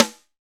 Index of /90_sSampleCDs/Roland L-CDX-01/SNR_Snares 4/SNR_Sn Modules 4
SNR RAP SN3.wav